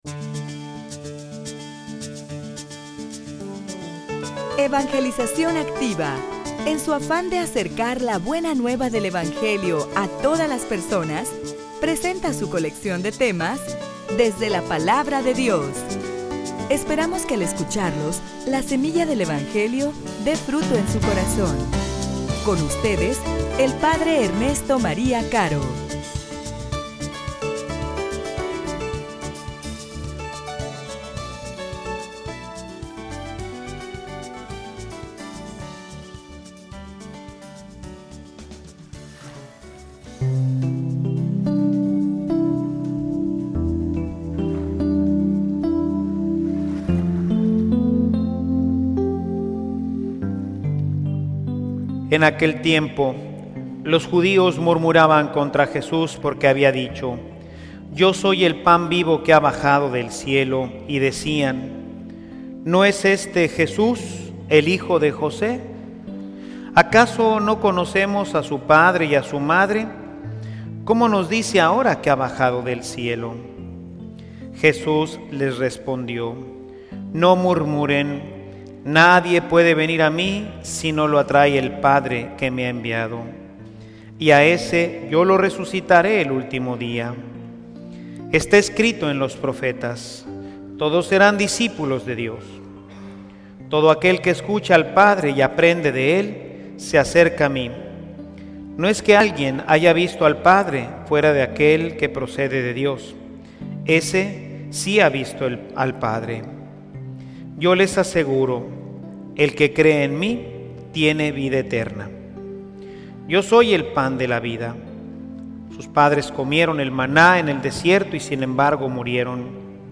homilia_La_necesidad_de_la_fe.mp3